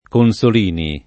[ kon S ol & ni ]